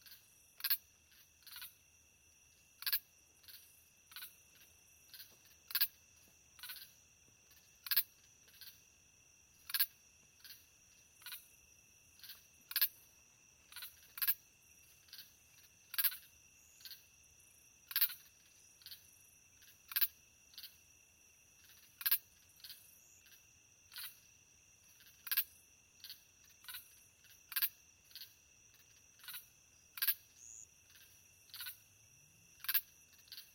insectday_18.ogg